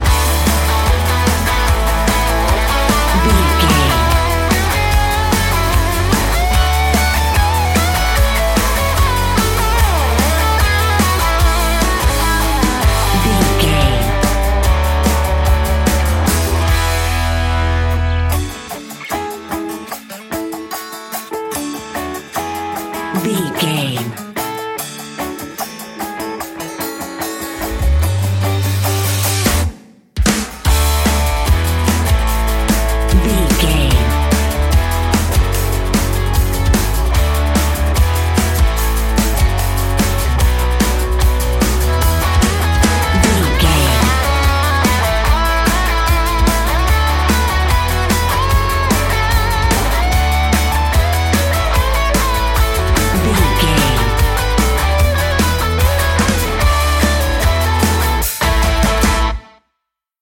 Ionian/Major
D
Fast
drums
electric guitar
bass guitar
Pop Country
country rock
bluegrass
happy
uplifting
powerful
driving
high energy